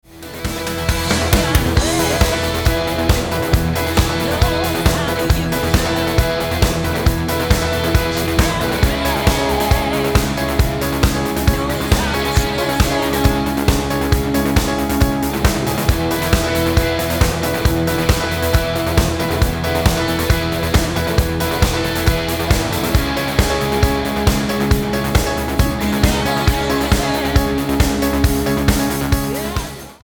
Tonart:E mit Chor
Die besten Playbacks Instrumentals und Karaoke Versionen .